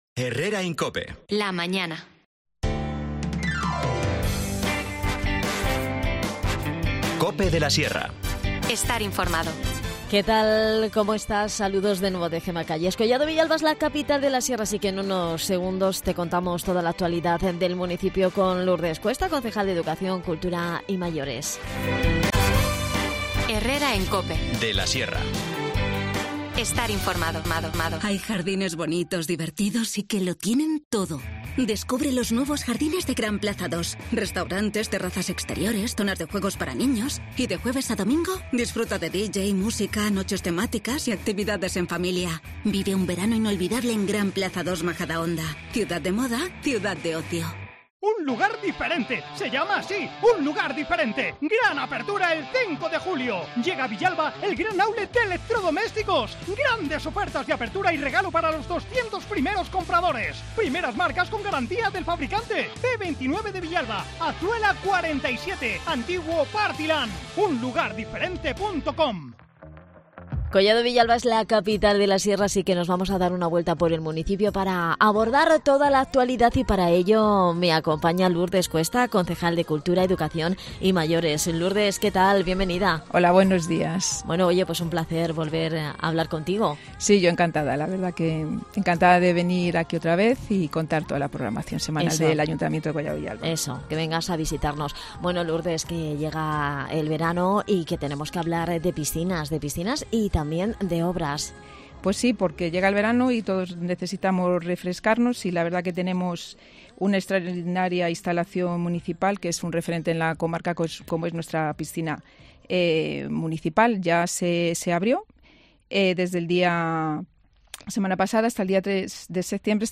INFORMACIÓN LOCAL
Lourdes Cuesta, concejal de Cultura, Educación y Mayores en Collado Villalba Capital de la Sierra, nos adelanta la actualidad del municipio que pasa por hablarnos de la obras de reparación del vaso , playas y zonas de vestuarios de la piscina cubierta del Centro Acuático que han contado con un presupuesto de 479.000€.